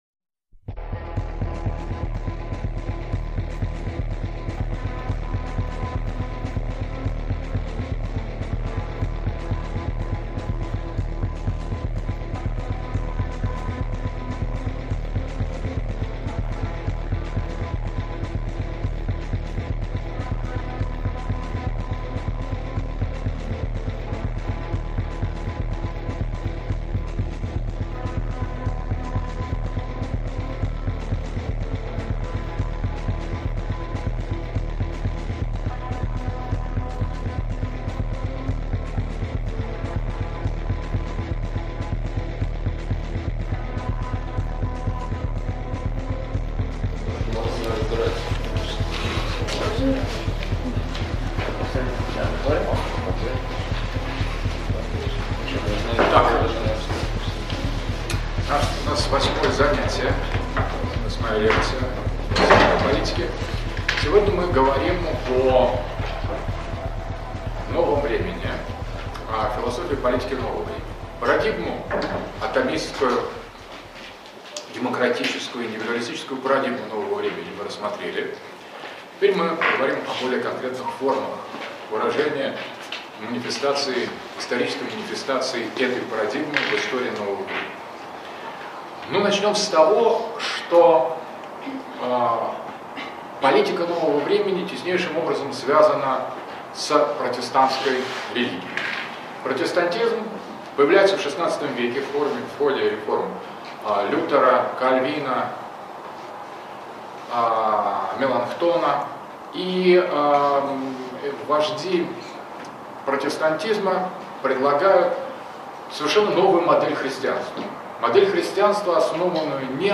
Философия Политики. Лекция № 8. Протестантизм и его секуляризация